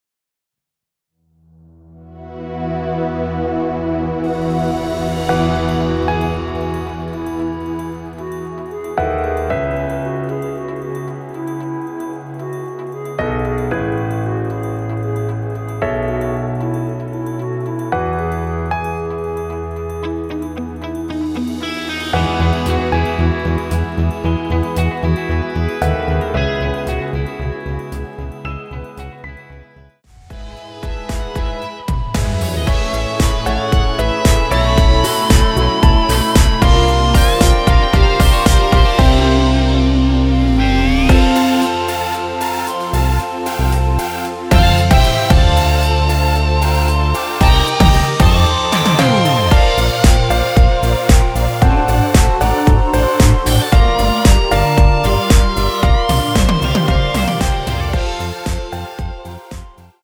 멜로디 MR입니다.
원키에서(+3)올린 멜로디 포함된 여성분이 부르실수 있는 키의 MR입니다.
멜로디 MR이라고 합니다.
앞부분30초, 뒷부분30초씩 편집해서 올려 드리고 있습니다.
중간에 음이 끈어지고 다시 나오는 이유는